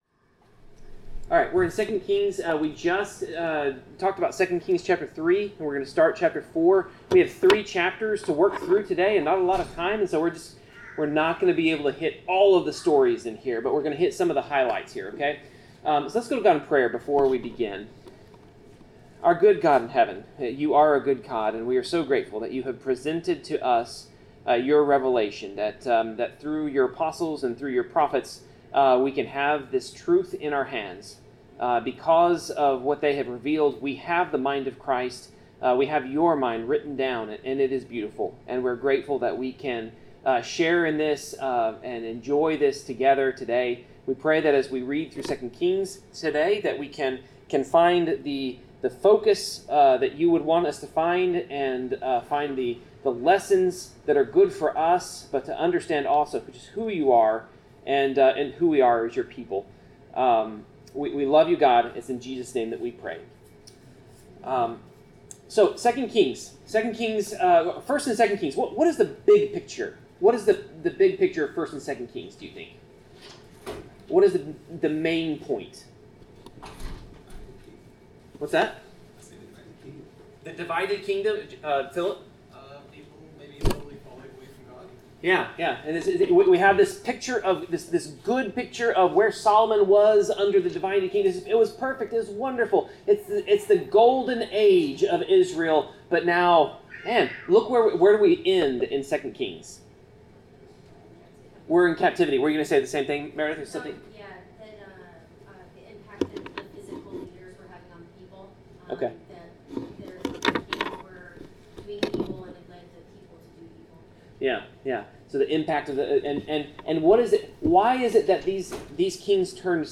Bible class: 2 Kings 4-5
Service Type: Bible Class Topics: God's Care , God's Messengers , God's Power , Humility , Jesus , Miracles , Obedience , Prayer , Resurrection , Trusting in God